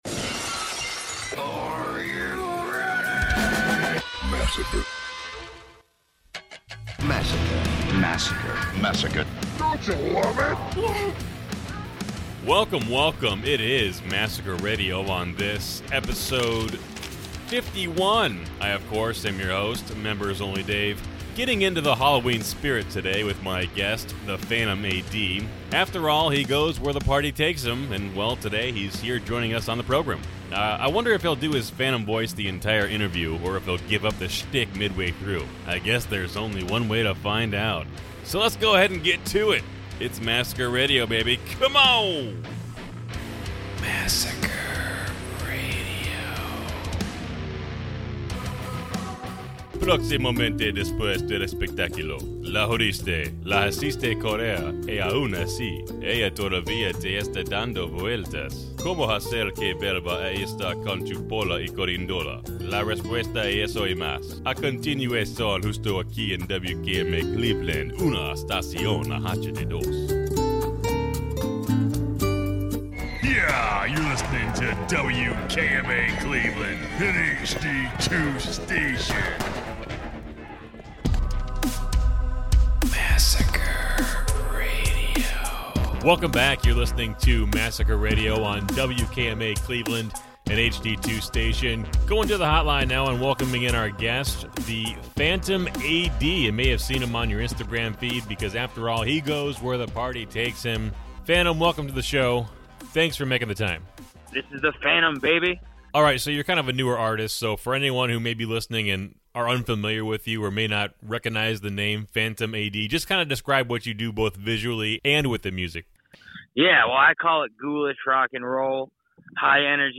Be a guest on this podcast Language: en Genres: Film History , Film Interviews , TV & Film Contact email: Get it Feed URL: Get it iTunes ID: Get it Get all podcast data Listen Now...